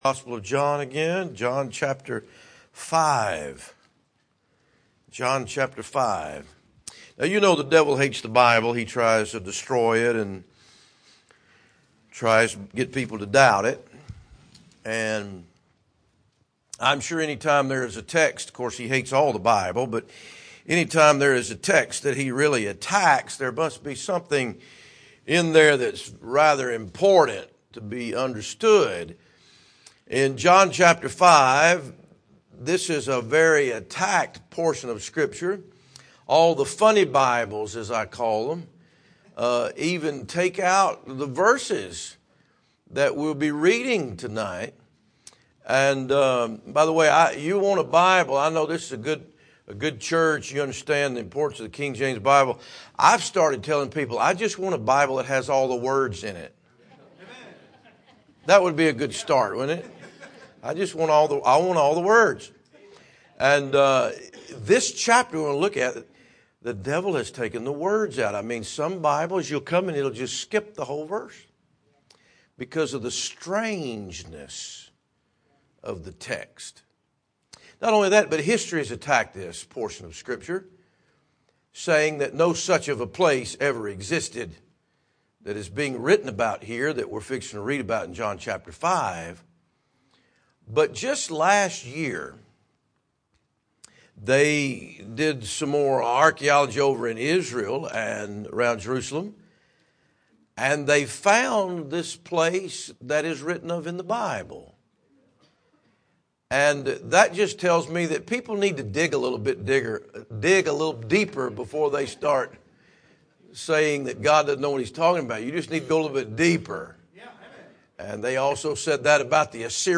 Sermons
missions conference